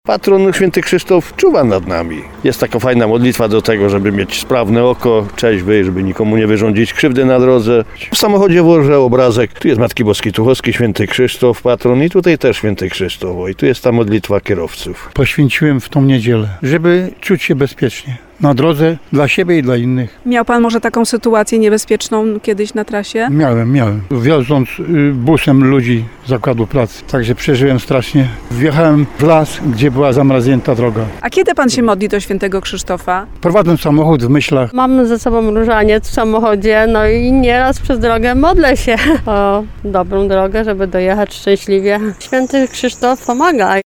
Mieszkańcy regionu, z którymi rozmawialiśmy mają w samochodach obrazki, breloczki z wizerunkiem św. Krzysztofa.